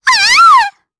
Pansirone-Vox_Damage_jp_03.wav